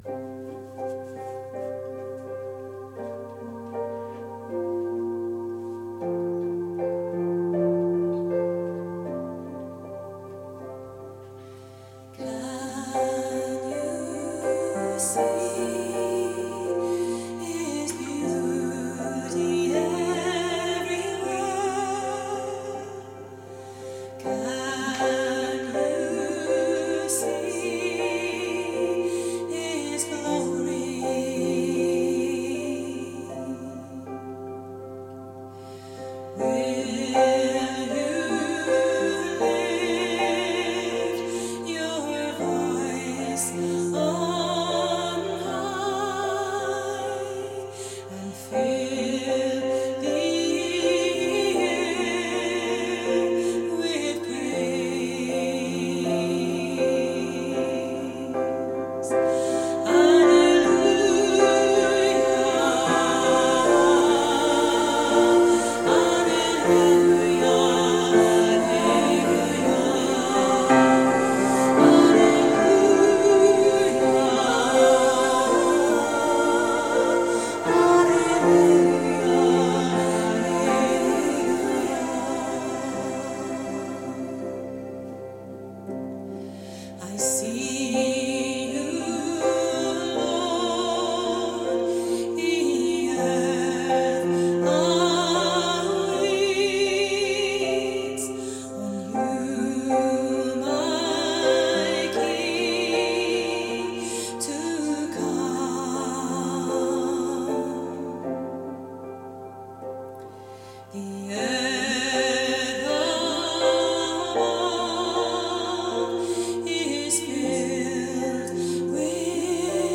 Posted in Christian, christian living, Christianity, church of scotland, end times, Jesus, pentecostal, revival, the remnant, the state of the church, theology, worship, worship music | 3 Comments »